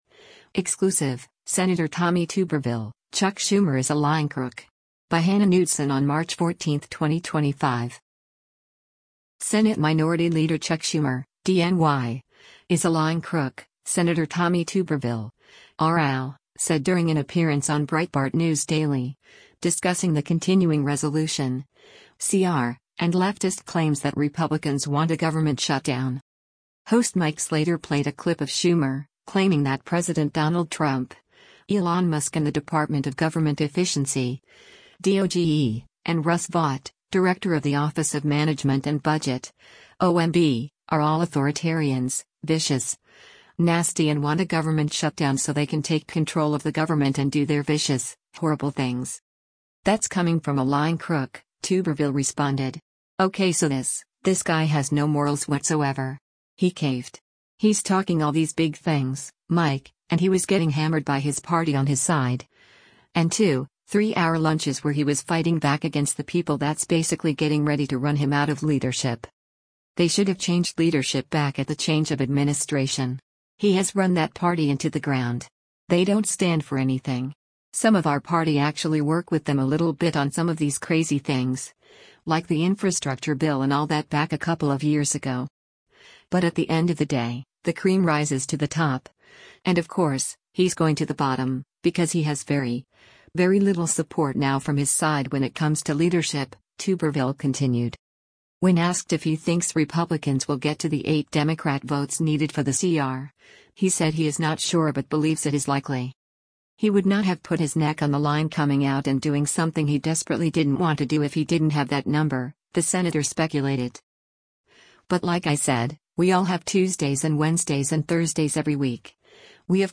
Senate Minority Leader Chuck Schumer (D-NY) is a “lying crook,” Sen. Tommy Tuberville (R-AL) said during an appearance on Breitbart News Daily, discussing the continuing resolution (CR) and leftist claims that Republicans want a government shutdown.